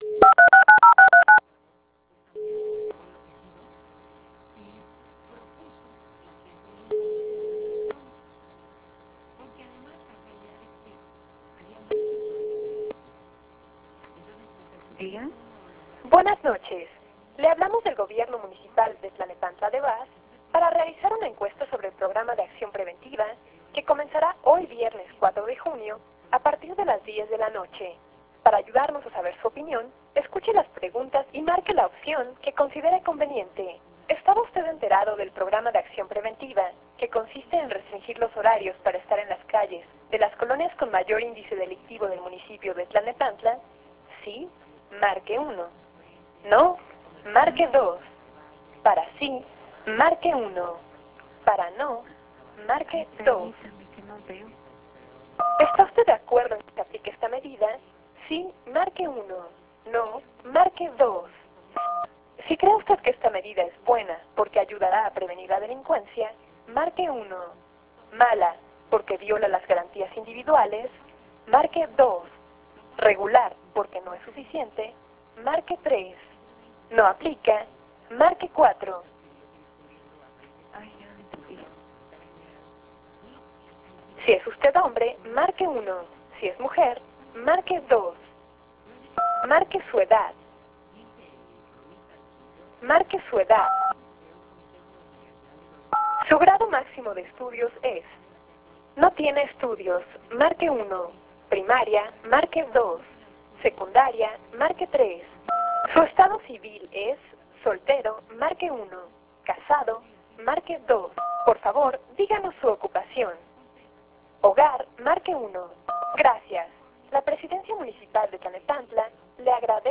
SISTEMA DE ENVIO DE MENSAJES MASIVOS VIA TELEFONICA PARA FINES DE PROMOCION, COBRANZA, ENCUESTAS, ETC.
encuesta-tlalnepantla.wav